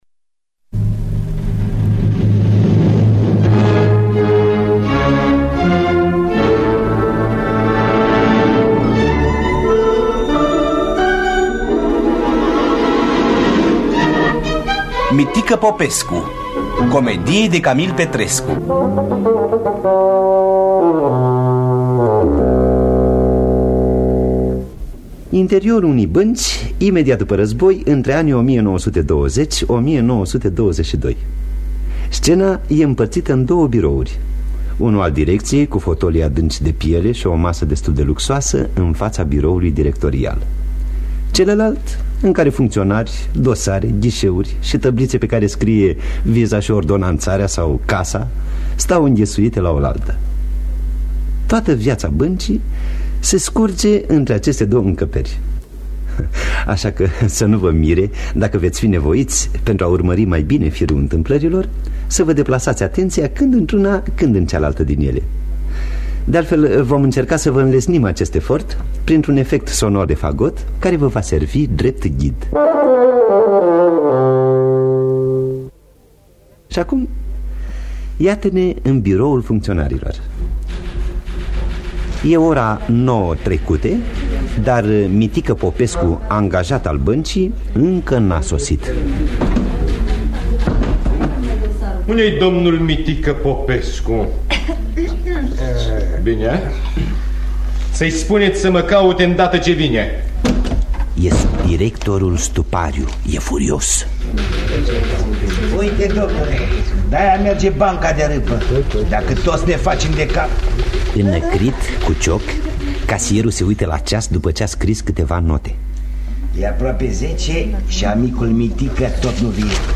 Mitică Popescu de Camil Petrescu – Teatru Radiofonic Online